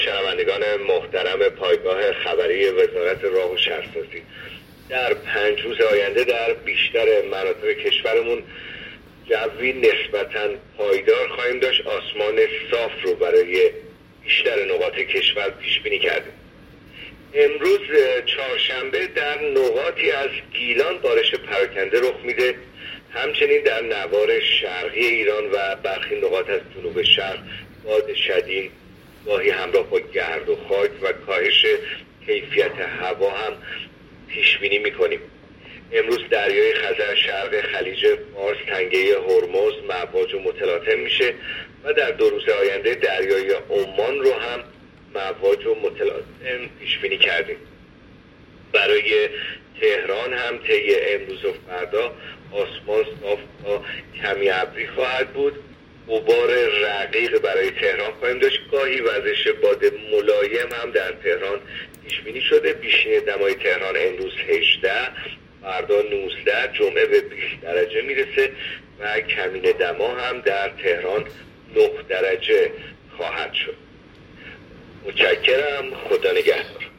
گزارش رادیو اینترنتی پایگاه‌ خبری از آخرین وضعیت آب‌وهوای چهاردهم آبان؛